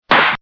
snap.mp3